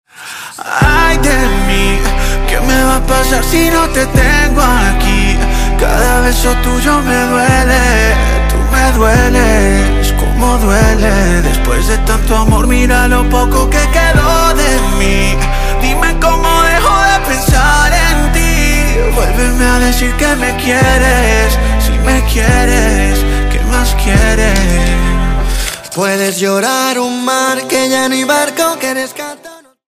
Categorie: Latin